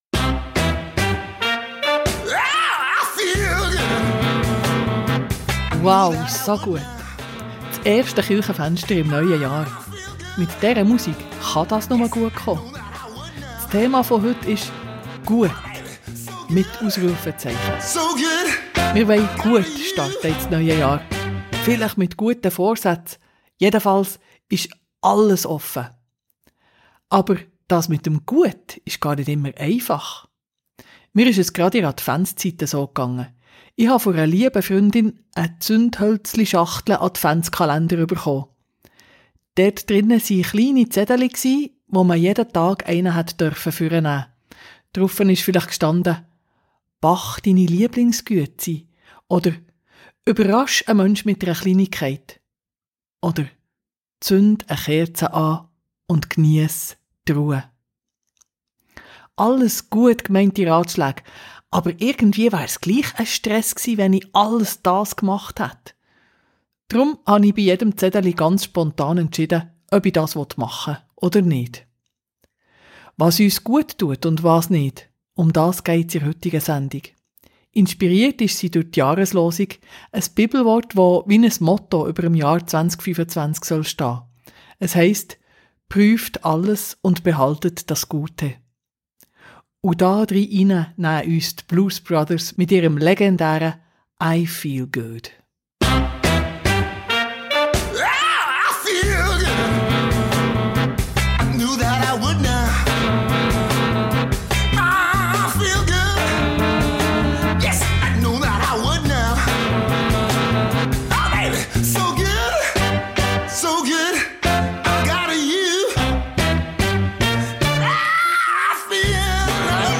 In Gedichten, Gesprächen mit Leuten wie du und ich und mit Musik gehen wir diesen Fragen nach. Eine Sendung, die aufstellt und den Blick auf das Gute richtet.